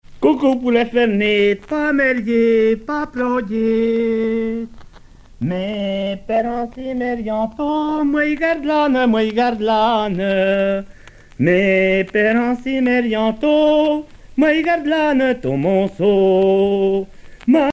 Genre brève
Pièce musicale éditée